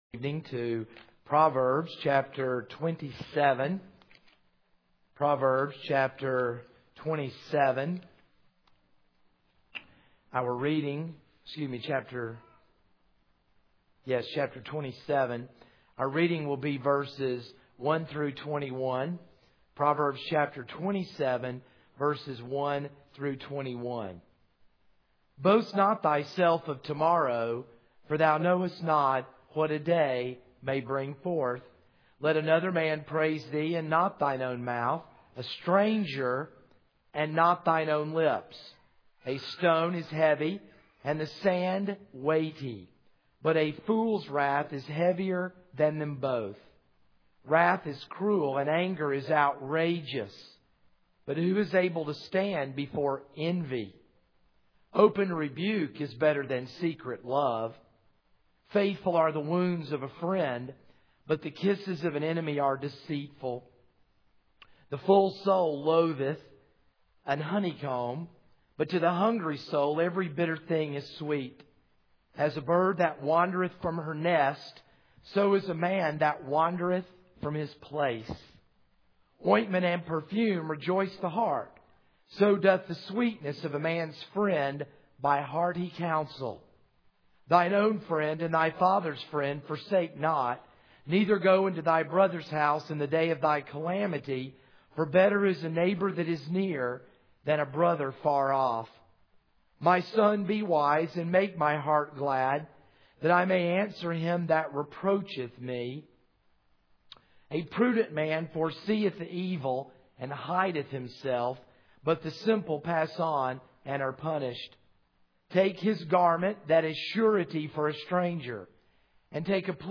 This is a sermon on Proverbs 27:1-10.